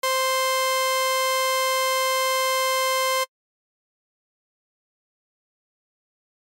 Designing a Saw Trance Lead in FL Studio
Next you need to set two oscillators to a saw wave and tune one of them up by just a few cents.